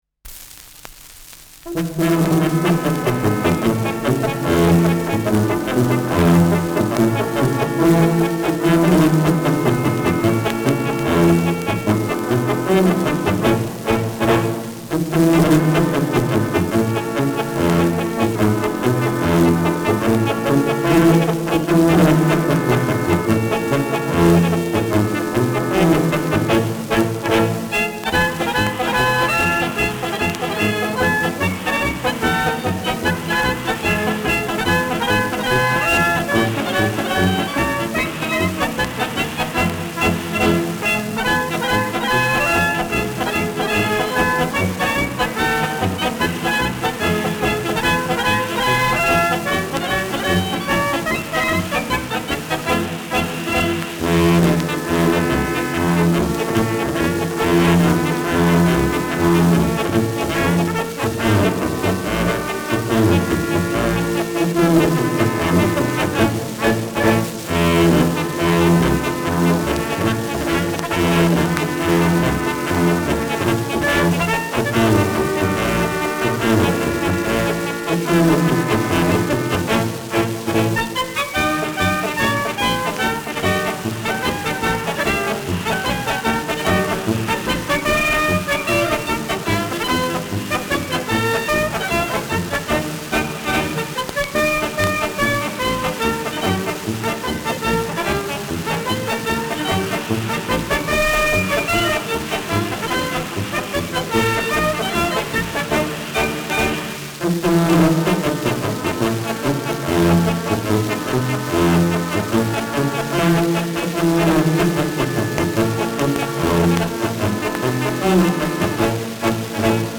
Schellackplatte
leichtes Rauschen : leichtes Knistern
[Salzburg] (Aufnahmeort)